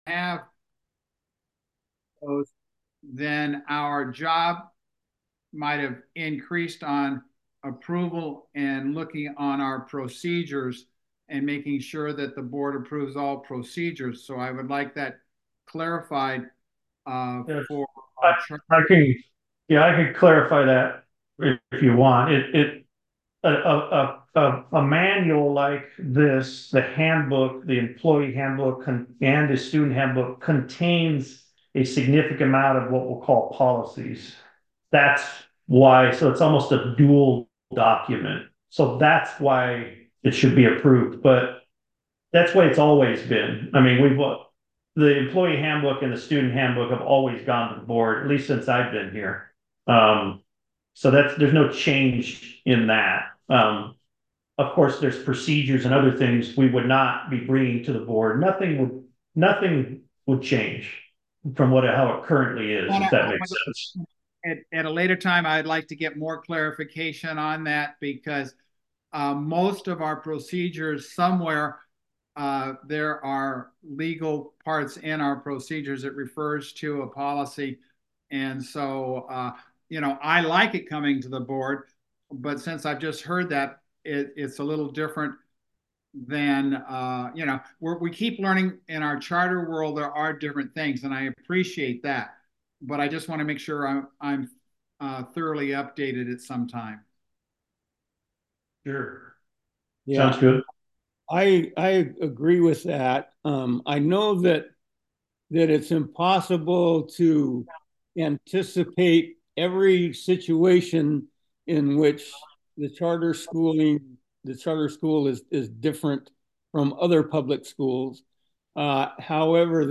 6.4.24-Regular-Board-Meeting.mp3